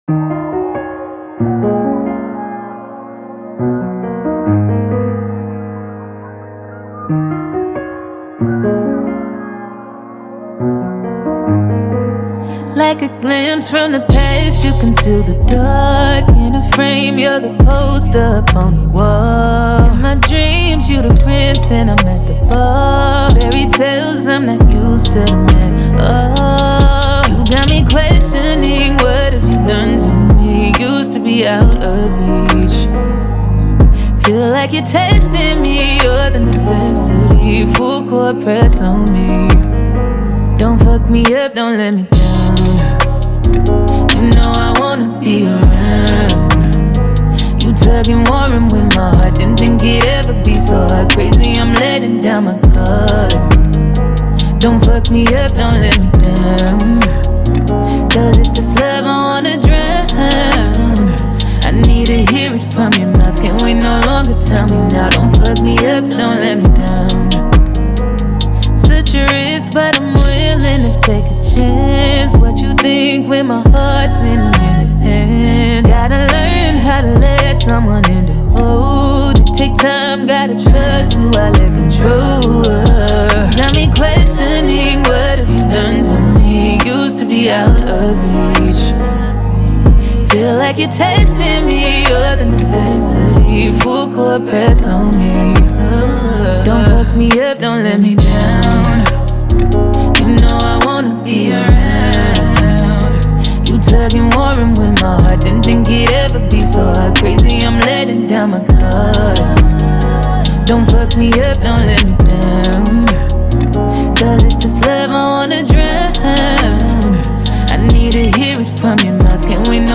English singer-songwriter